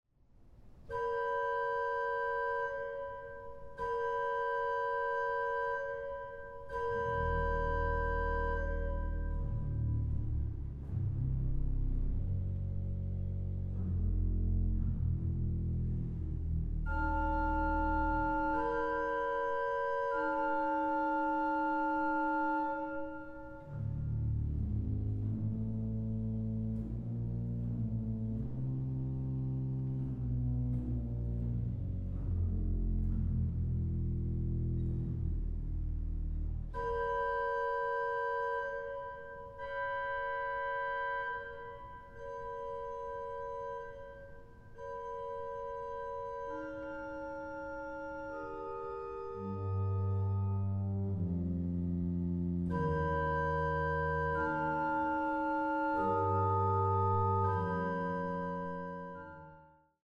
Orgel
Aufnahme: Het Orgelpark, Amsterdam, 2023